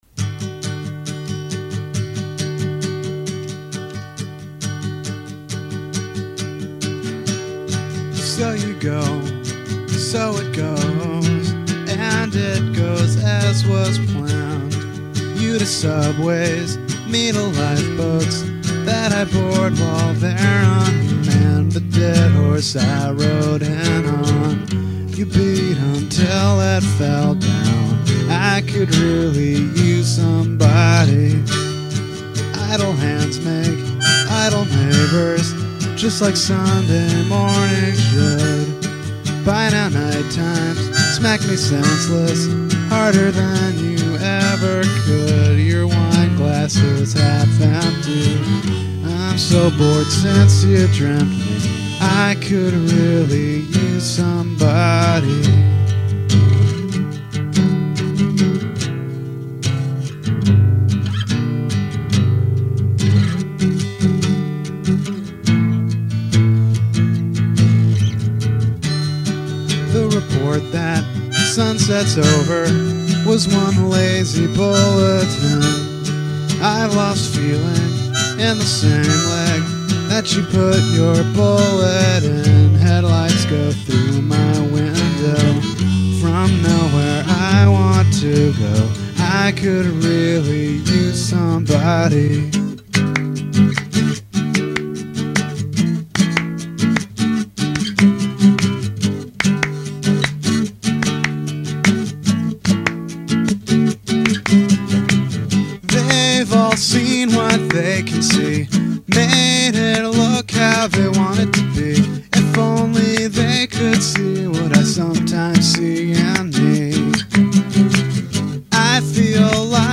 Your Minneapolis-based musician and songwriter